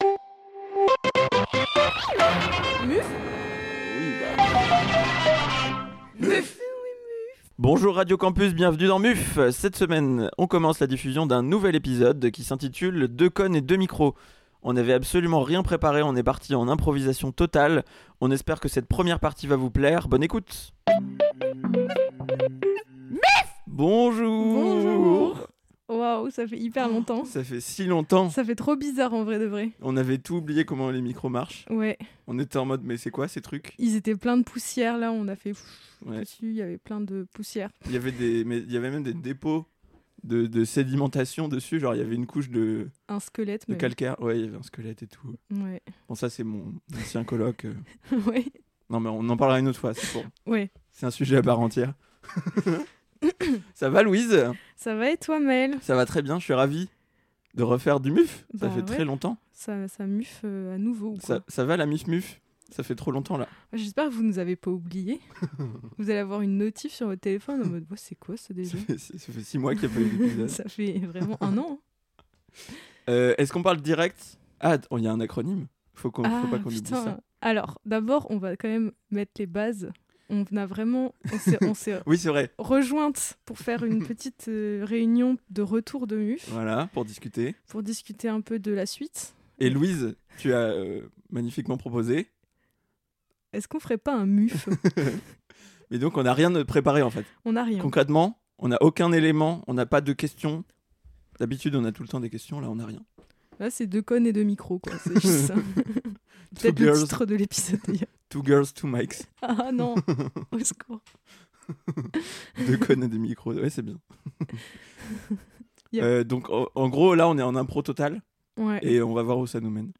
Cette semaine, un épisode juste à deux ! Pas d’invité, et pas de plan non plus : on part dans une discussion improvisée…